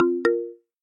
Рингтоны на СМС